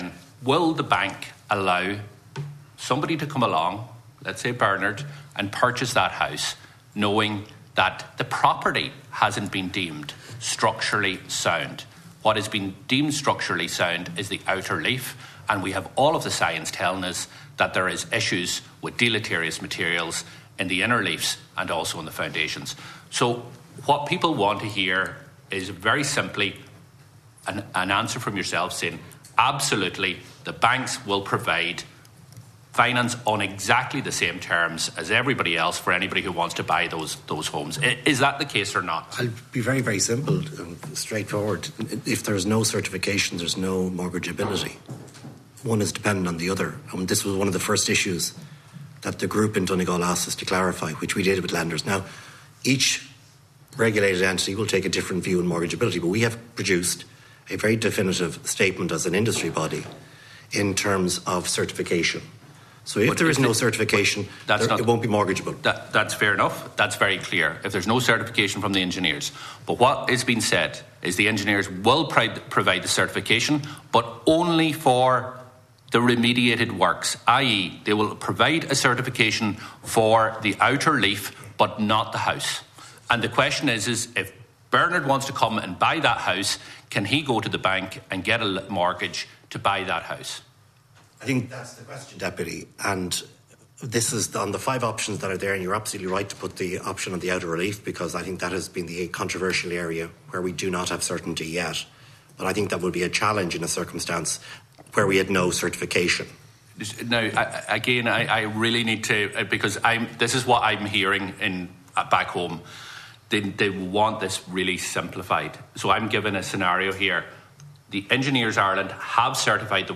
An Oireachtas committee to discuss issues related to Defective Concrete Blocks has gotten underway.